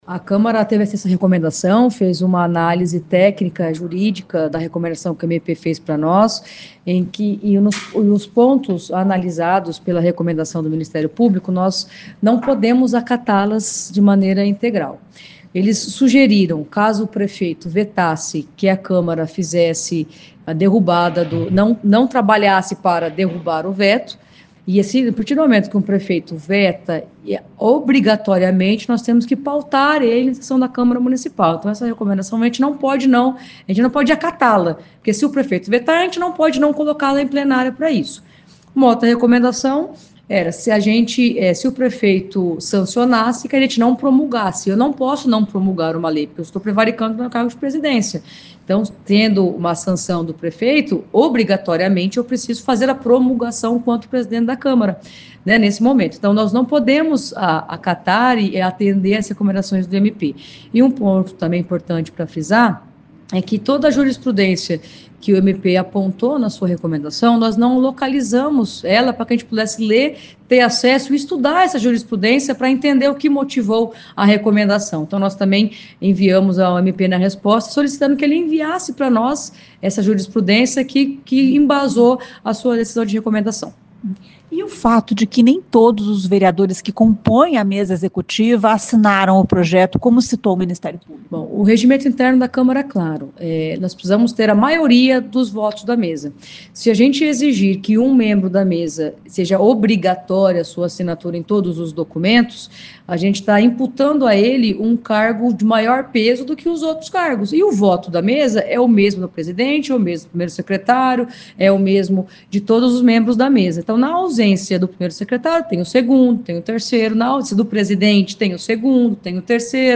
A presidente Majô explica que todos os pontos apontados pelo MP foram justificados com base no regimento interno da Câmara. Ouça o que diz a presidente: